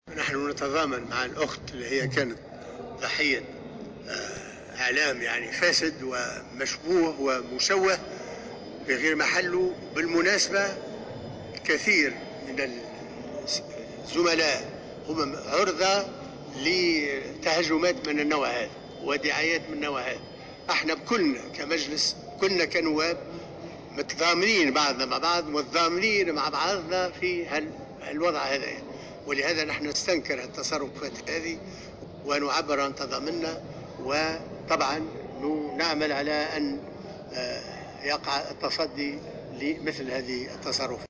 عبر رئيس مجلس النواب محمد الناصر اليوم الثلاثاء 7 نوفمبر 2017 خلال جلسة عامة...